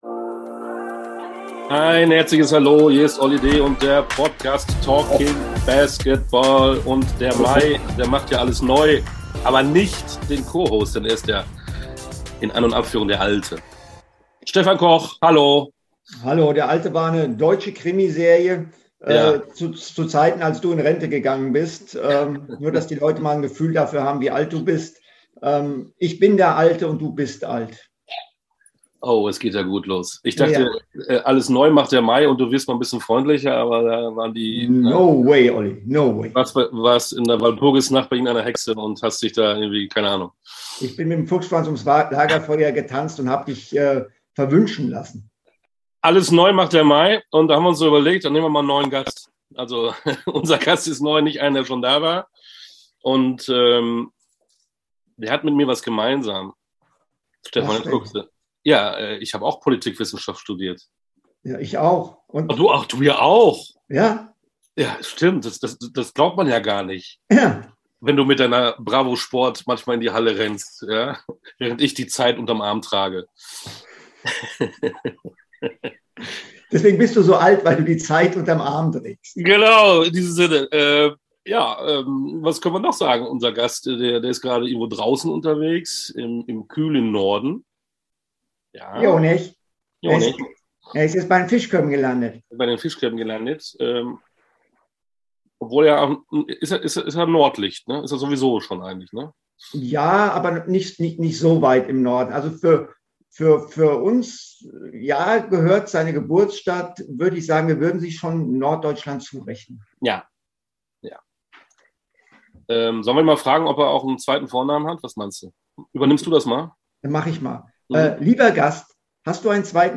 Zwei Gastgeber, ein Gast!